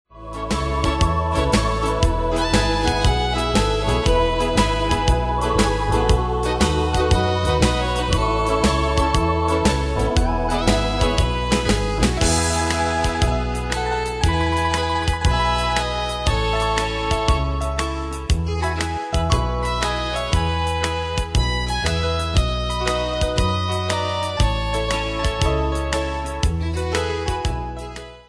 Style: Country Ane/Year: 1978 Tempo: 118 Durée/Time: 3.49
Danse/Dance: TripleSwing Cat Id.
Pro Backing Tracks